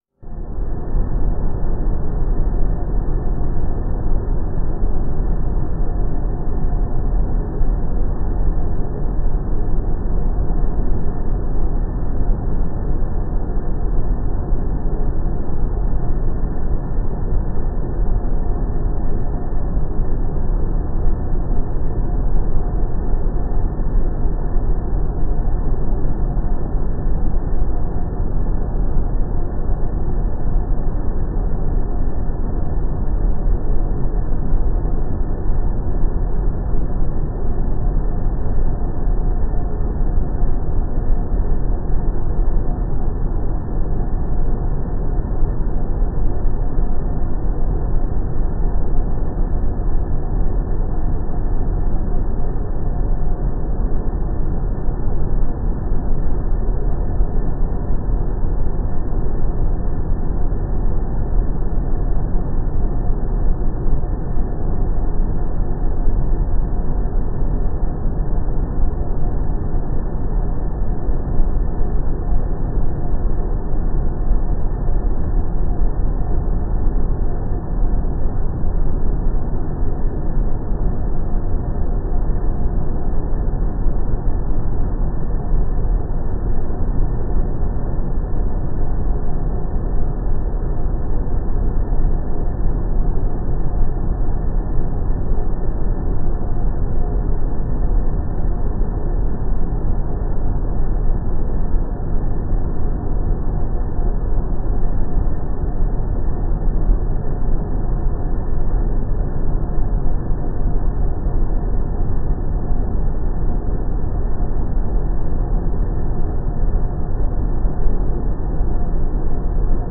Звуки кремации
Тихий звук крематорной обстановки